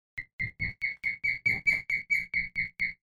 Vous pouvez écouter le cri du balbuzard pêcheur en cliquant sur le lien suivant :
Il est reconnaissable facilement et c’est par son cri que j’ai trouvé l’emplacement de son nid.